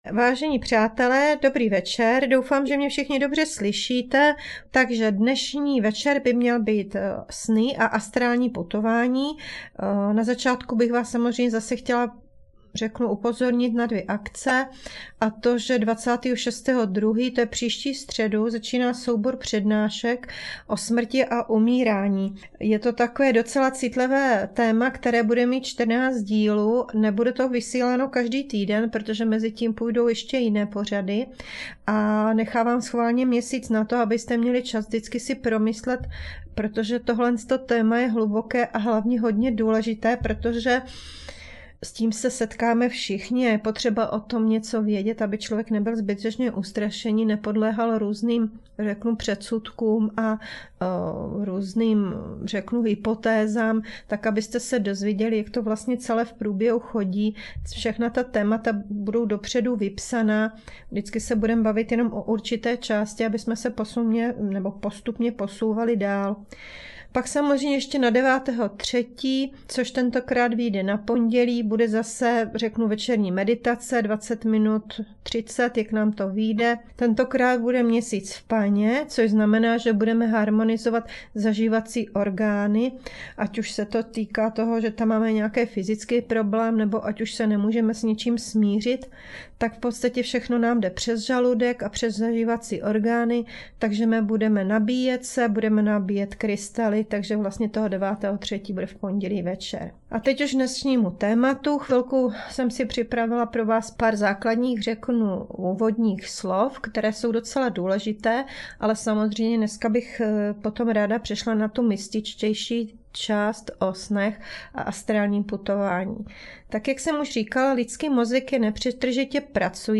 Na závěr Vám vždy formou malé meditace pošlu světlo, aby jste se cítili klidně a v pohodě.